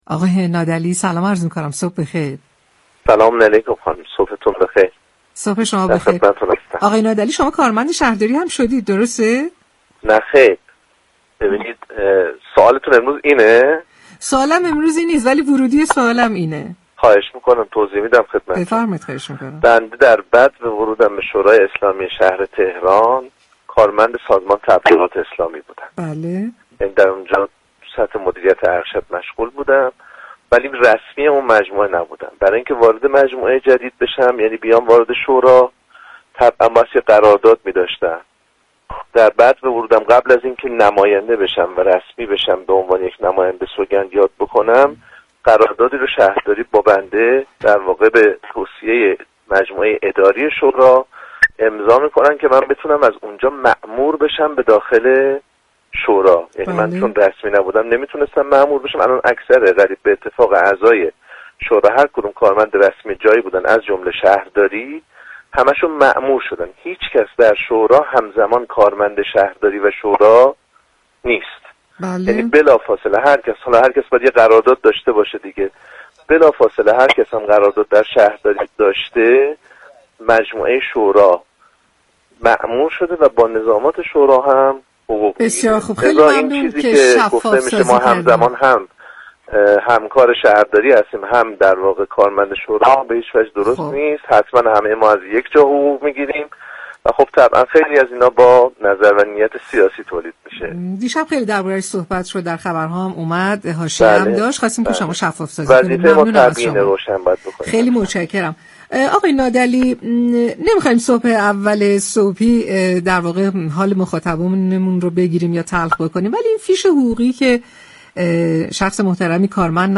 به گزارش پایگاه اطلاع رسانی رادیو تهران، علیرضا نادعلی سخنگوی شورای اسلامی شهر تهران در ارتباط با پرداخت حقوق 33میلیون تومانی به یكی از كارمندان شورای شهر تهران؛ در گفت و گو با « شهر آفتاب» رادیو تهران اینگونه توضیح داد: بسیاری از معوقات كاركنان شورای شهر در فیش حقوقی آذرماه پرداخت شده و مبلغ دریافتی آنها بالا رفته است.